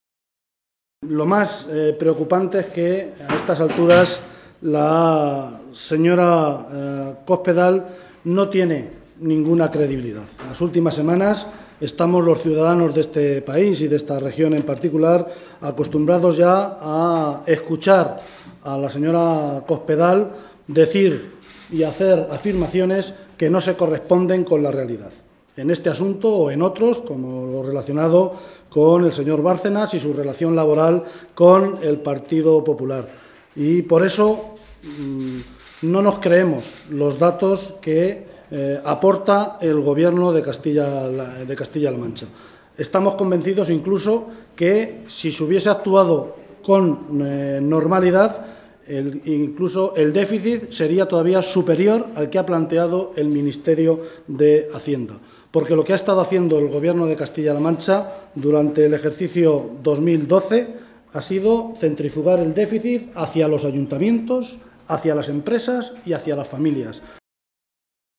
Guijarro se pronunciaba de esta manera esta tarde, en el Parlamento regional, en una comparecencia ante los medios de comunicación durante la celebración del Pleno de hoy, en la que valoraba los datos de déficit hechos públicos por el Ministerio de Hacienda, que señalan que nuestra región no ha cumplido el objetivo de déficit, al situarse por encima del 1,5 por ciento sobre el PIB exigido y dejarlo en el 1,53 por ciento.
Cortes de audio de la rueda de prensa